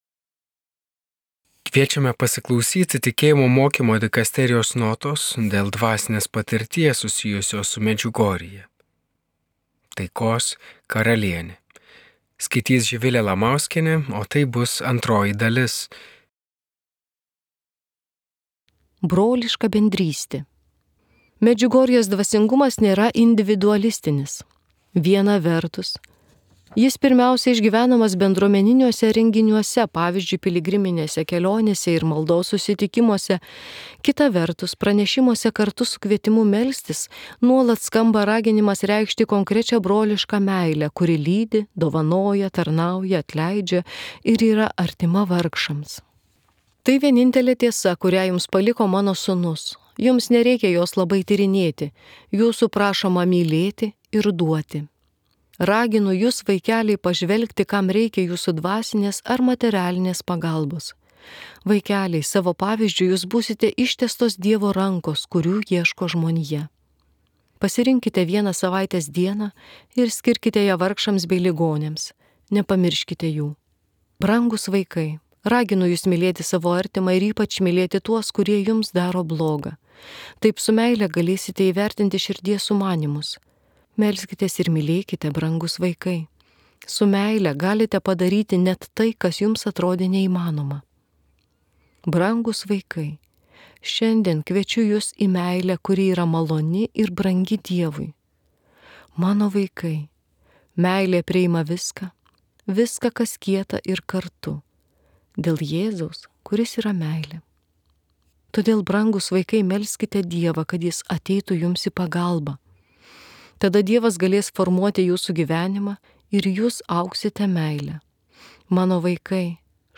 استمع إلى Katechezė.